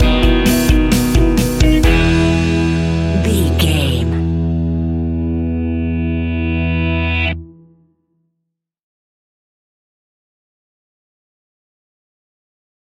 Ionian/Major
pop rock
indie pop
fun
energetic
uplifting
instrumentals
guitars
bass
drums
piano
organ